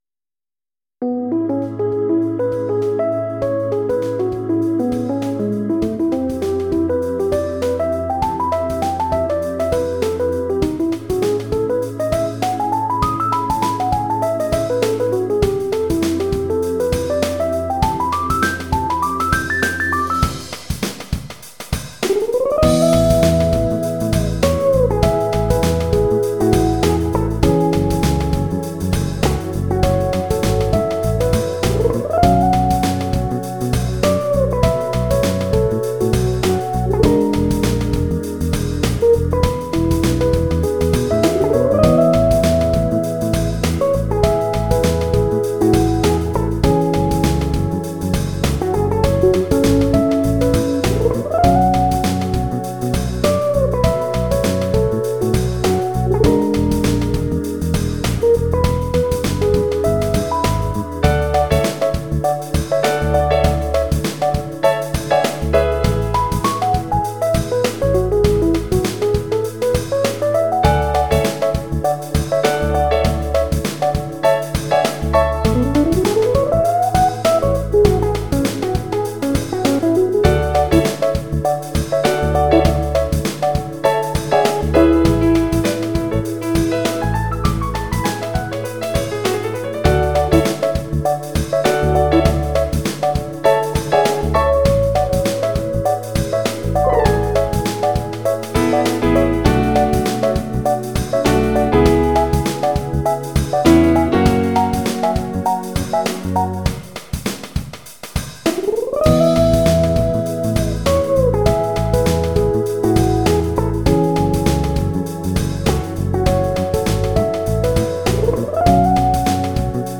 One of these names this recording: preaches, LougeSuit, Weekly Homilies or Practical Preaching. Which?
LougeSuit